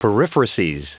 Prononciation du mot periphrases en anglais (fichier audio)
Prononciation du mot : periphrases